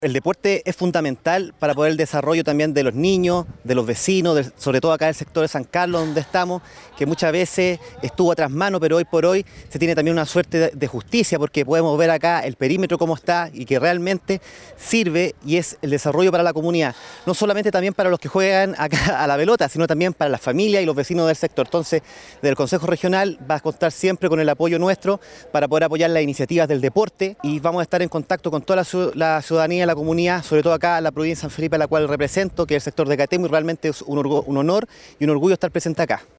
El Consejero Regional por la Provincia de San Felipe, Fernando Astorga valoró la relevancia de apoyar actividades vinculadas al deporte.
Consejero-Fernando-Astorga.mp3